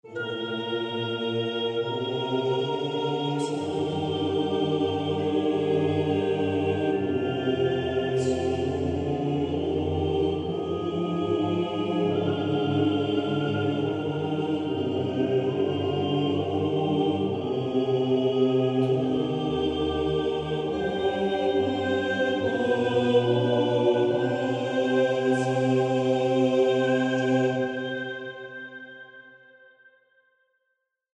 classique - orchestre - opera - vieux - chef